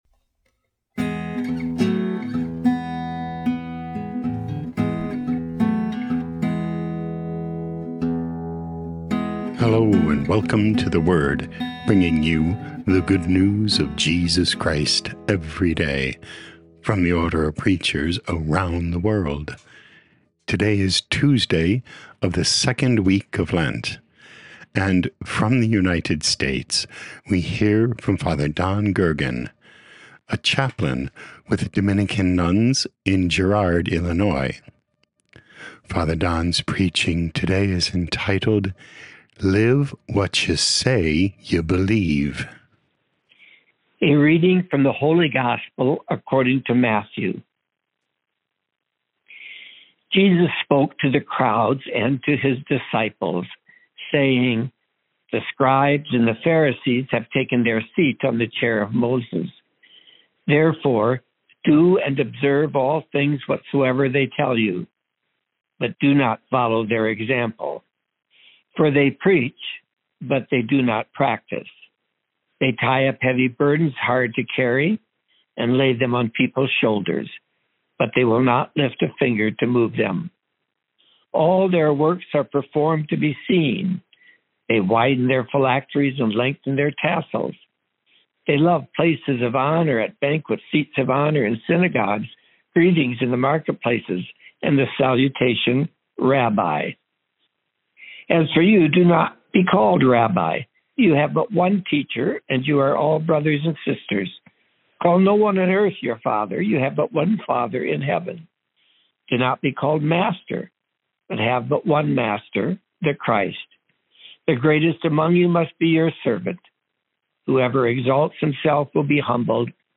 18 Mar 2025 Live What You Say You Believe Podcast: Play in new window | Download For 18 March 2025, Tuesday of the 2nd week of Lent, based on Matthew 23:1-12, sent in from Girard, Illinois, USA.
O.P. Preaching